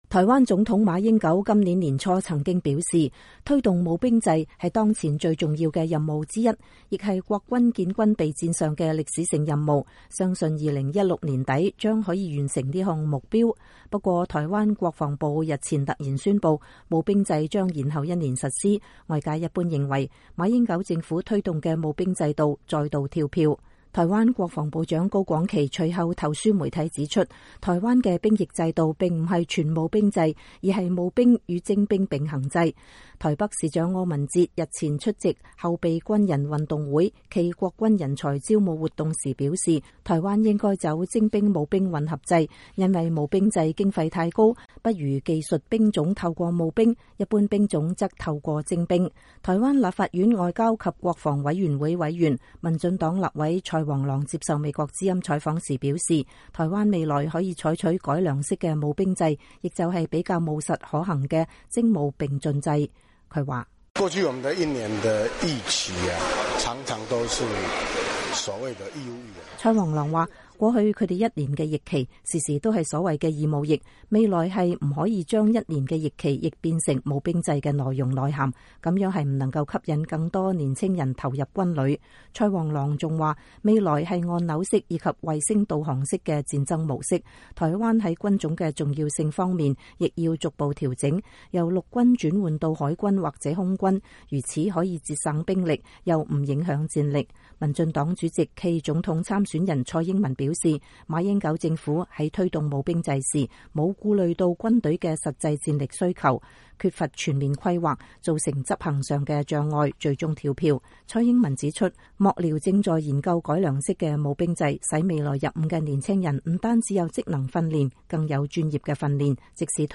台灣立法院外交及國防委員會委員、民進黨立委蔡煌瑯接受美國之音採訪時表示，台灣未來可以採取改良式的募兵制，也就是比較務實可行的徵募並進制度。